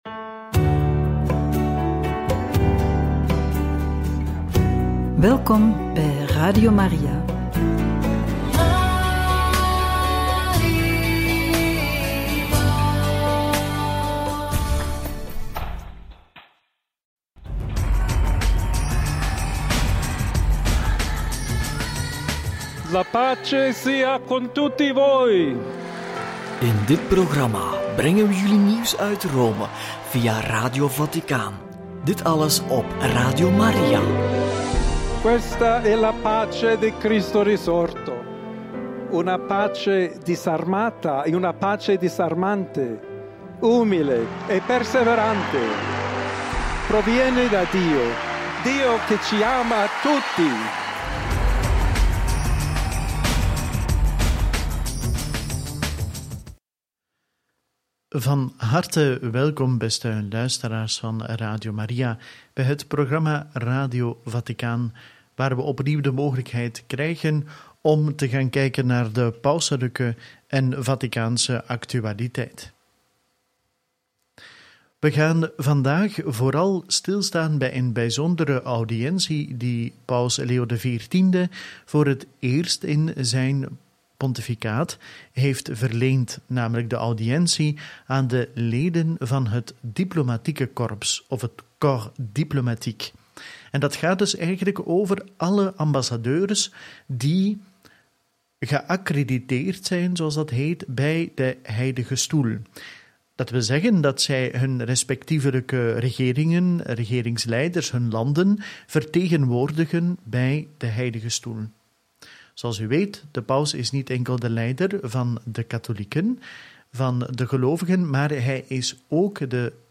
Paus Leo XIV geeft belangrijke toespraak tot het Corps Diplomatique en brengt zijn nieuwjaarswensen over – Deel 1 – Radio Maria